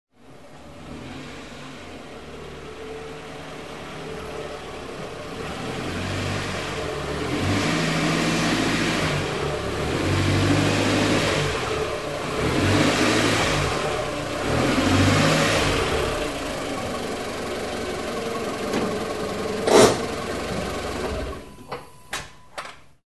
На этой странице собраны звуки парктроника — сигналы, которые издает автомобиль при парковке или обнаружении препятствий.
Звук парковки машины задним ходом